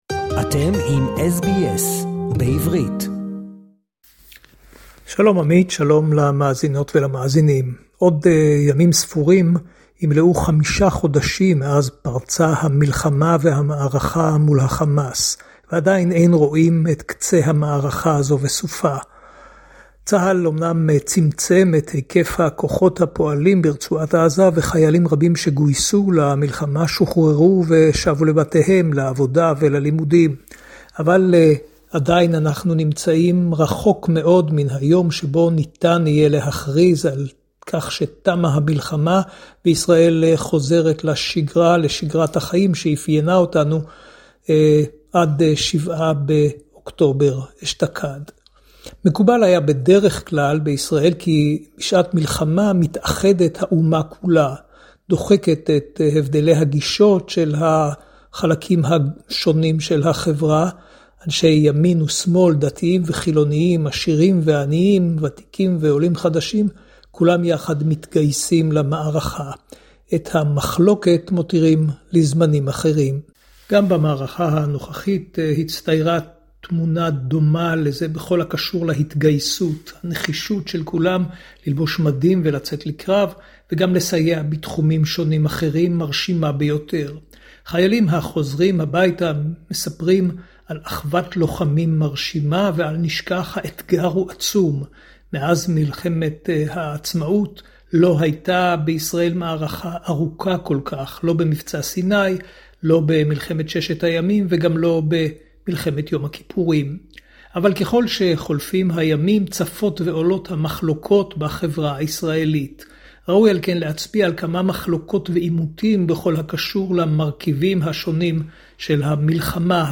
reporting to SBS Hebrew, with news and current affairs from Israel (23.2.24)